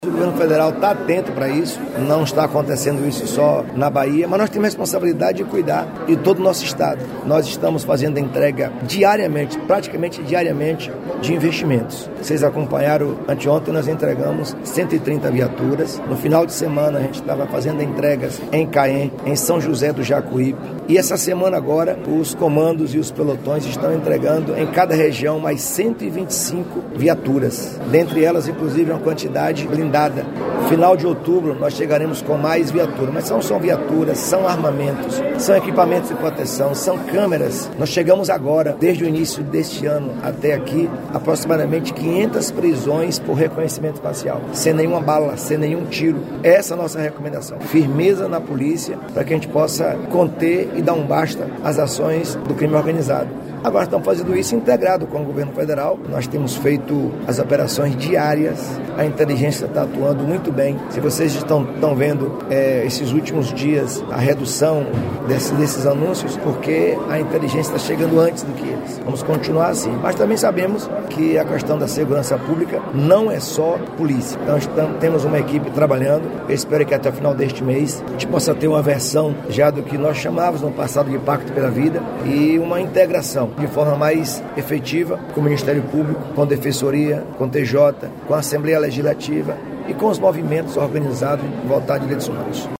🎙Sonora do Governador da Bahia Jerônimo Rodrigues